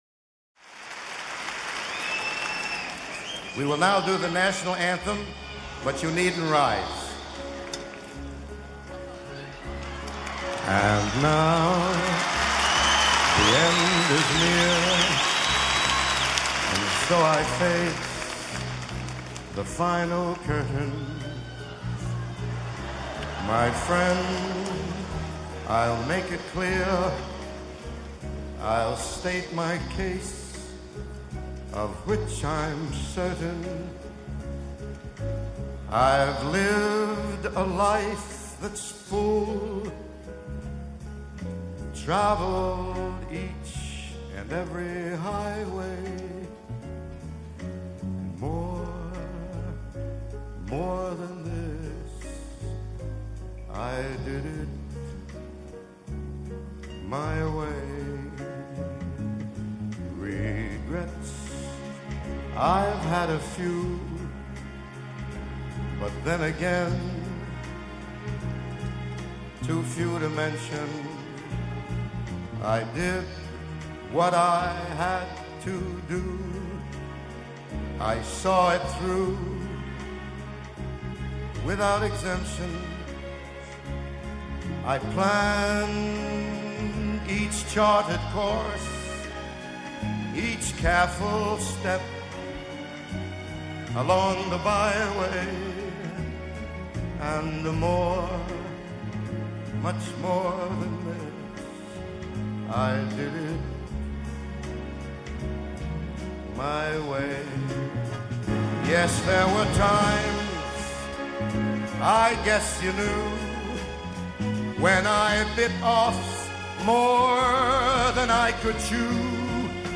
Live At Madison Square Garde - New York City